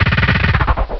DRILL1.WAV